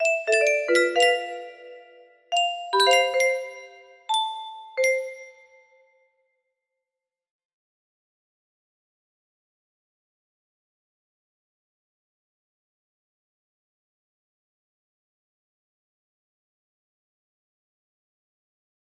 dream music box melody